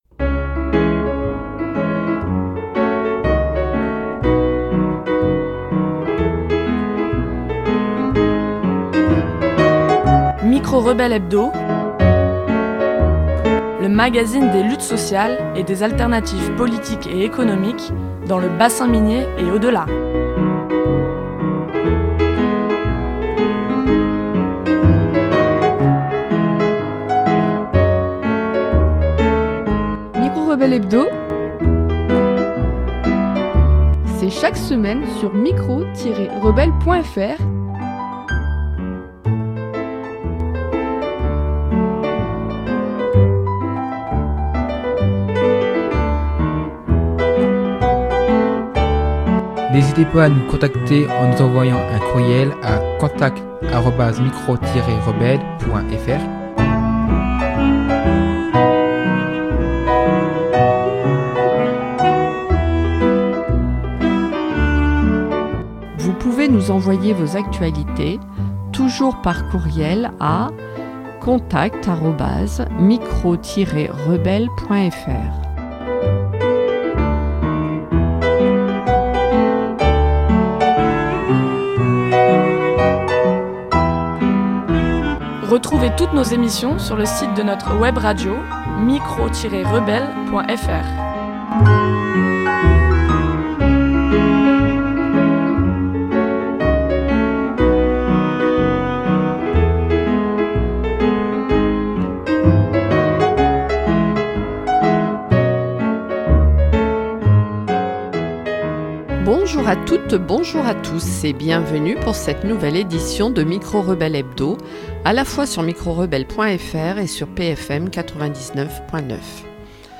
C’est notre émission hebdomadaire, diffusée également sur PFM 99.9